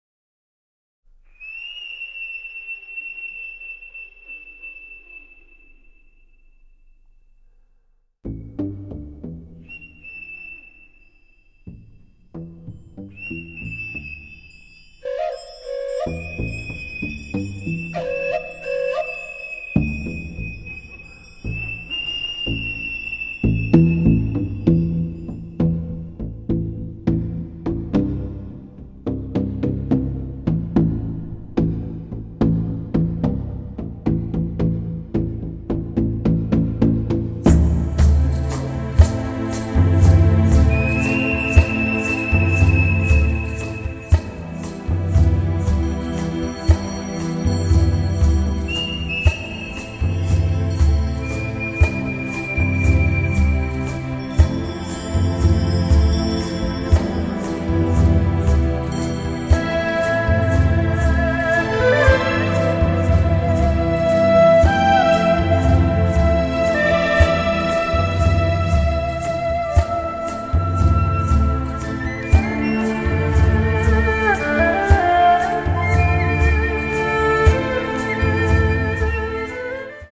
Sound Track
SYNTHESIZER
笛
二胡
PERCUSSIONS
KEYBORADS
GUITAR